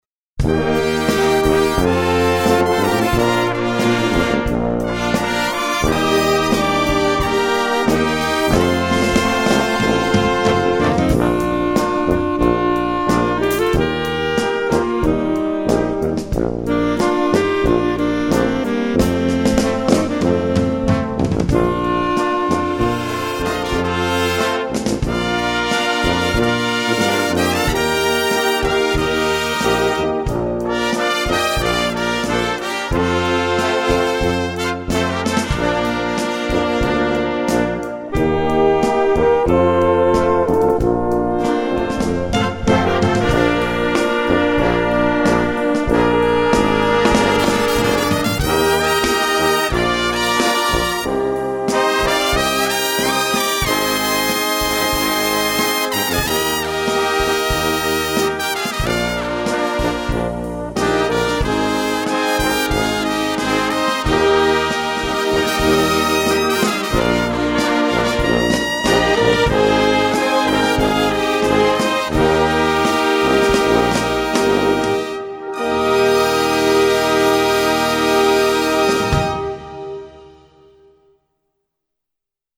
Gattung: Slowrock
Besetzung: Blasorchester